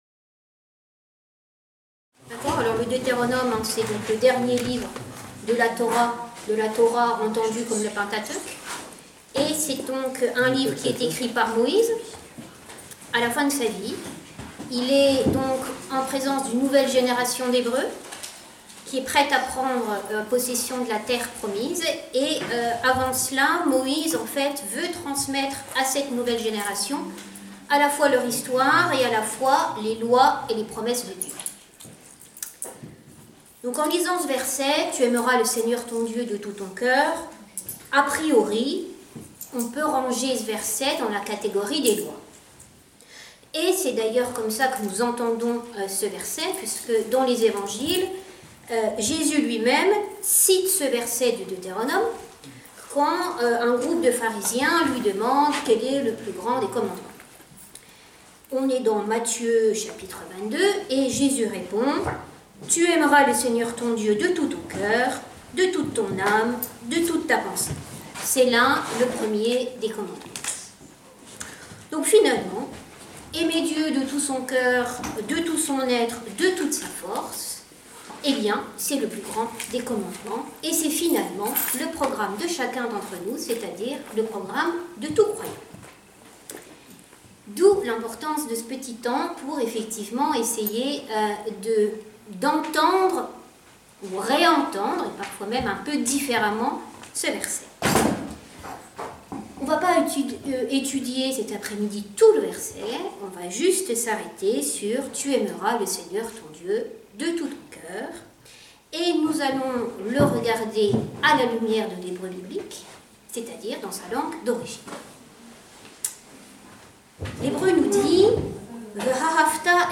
Étude biblique